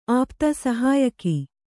♪ āpta sahāyaki